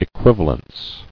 [e·quiv·a·lence]